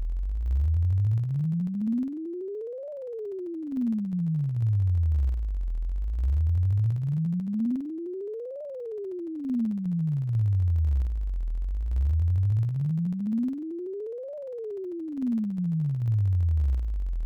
GenerateSweepWaveFile